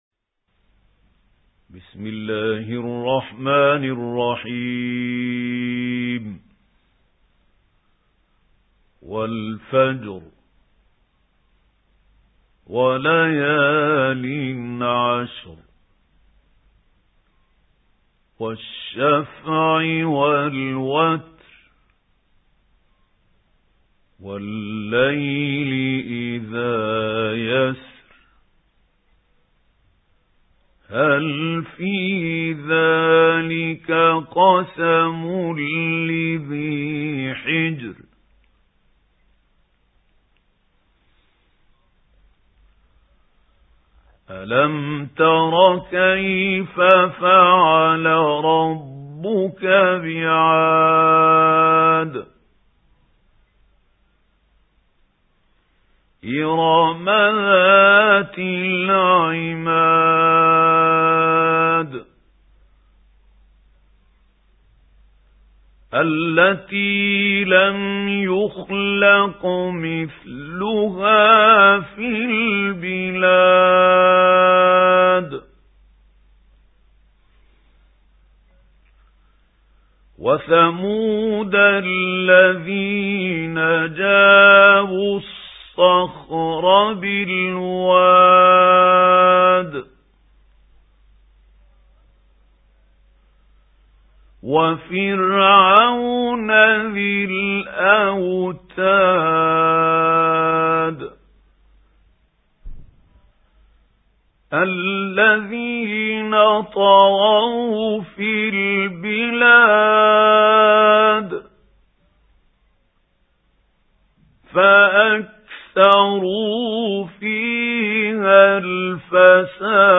سُورَةُ الفَجۡرِ بصوت الشيخ محمود خليل الحصري